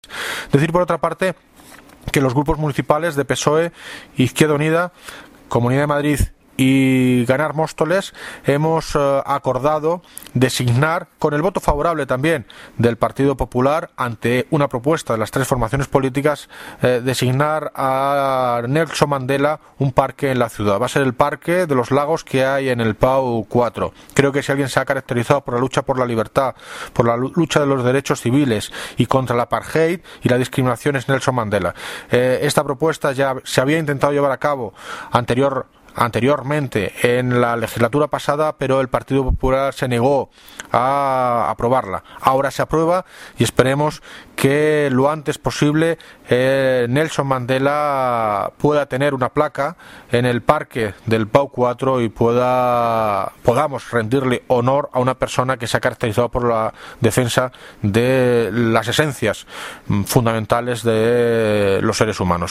Audio de David Lucas, Alcalde de Móstoles